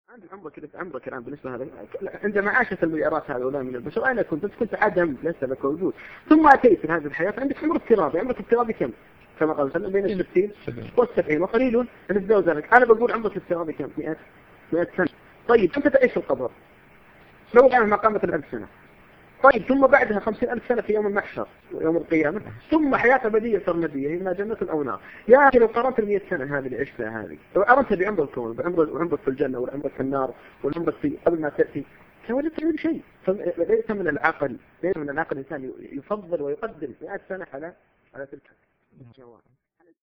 هذا مقطع من لقاء مع أحد المشائخ أو القراء أو المنشدين ..